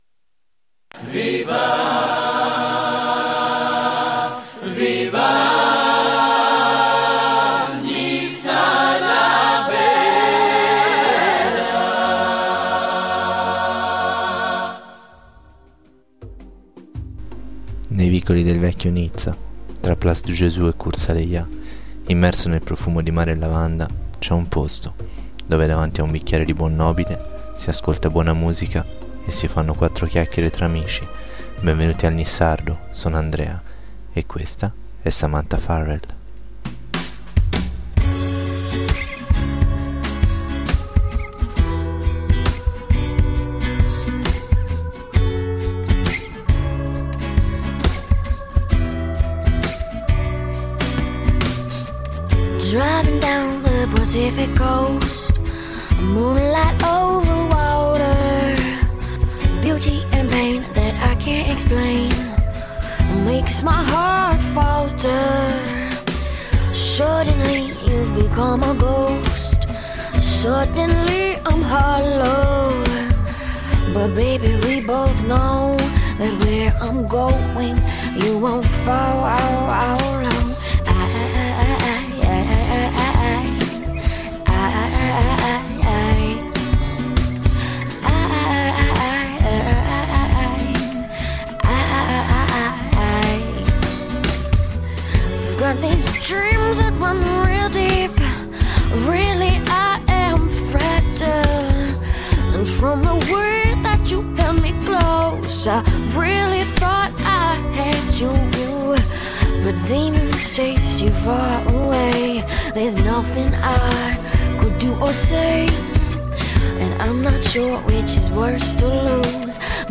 Tra i vicoli del vecchio Nizza, sorseggiando un bicchiere di buon chianti, si ascolta buona musica e si fanno 4 chiacchiere tra amici.